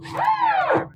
airbus_ptu_on.wav